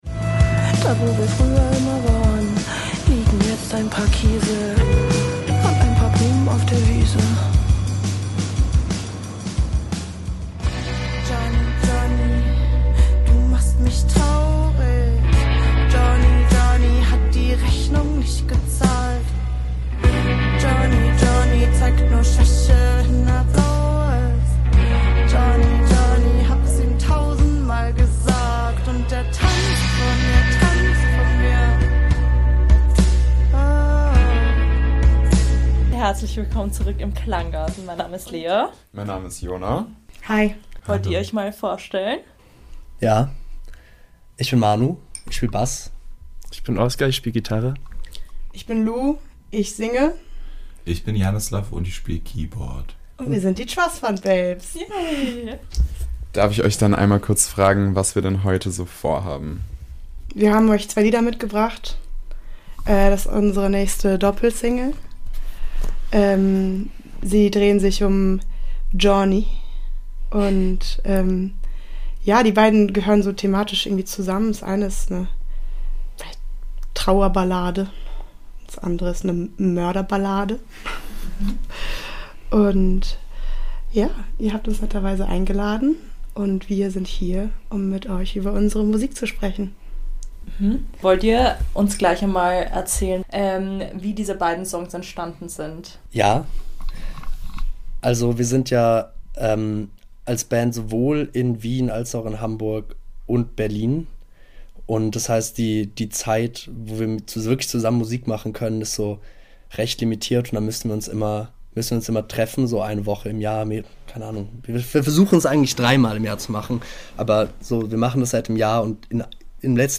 "Johnny" - ein Songgespräch mit Trustfundbabes ~ Klanggarten Podcast